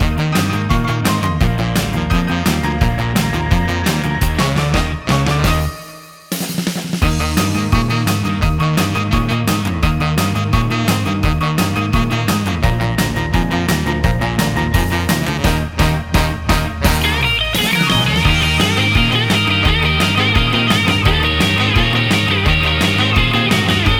no Backing Vocals Rock 'n' Roll 2:27 Buy £1.50